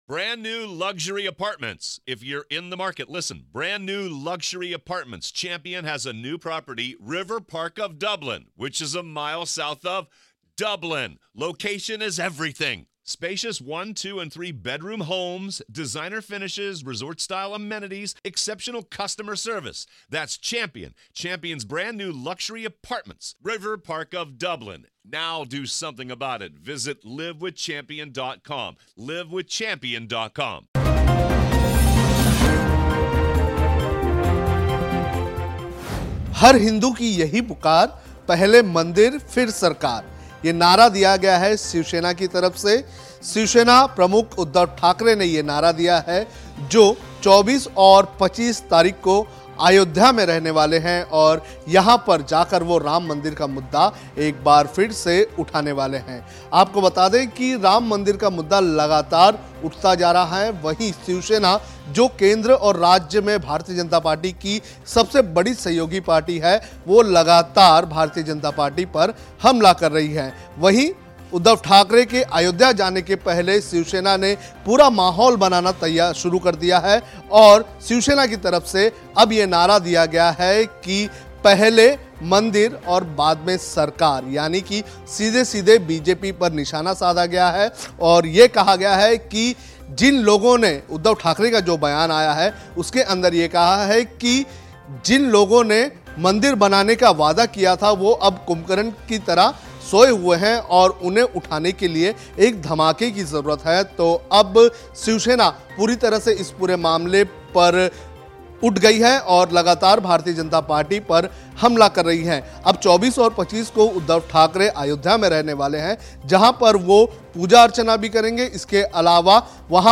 न्यूज़ रिपोर्ट - News Report Hindi / हर हिंदू की यही पुकार, पहले मंदिर फिर सरकार : शिवसेना